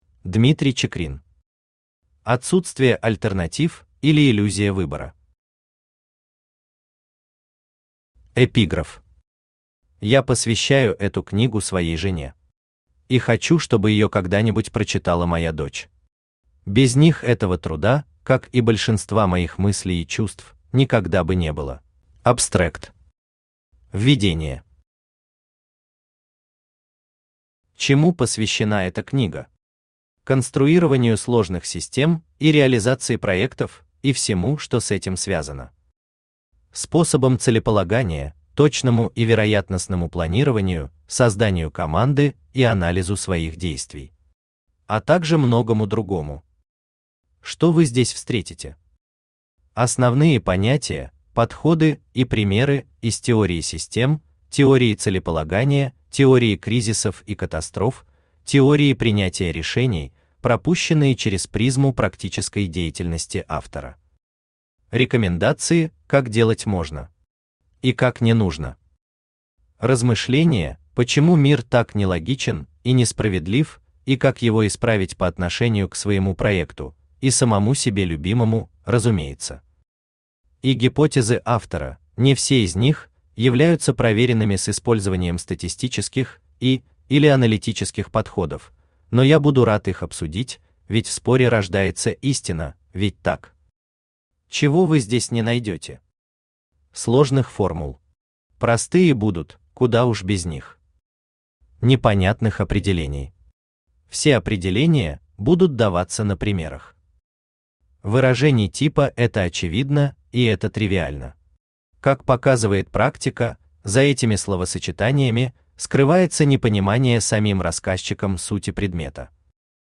Aудиокнига Отсутствие альтернатив, или Иллюзия выбора Автор Дмитрий Евгеньевич Чикрин Читает аудиокнигу Авточтец ЛитРес.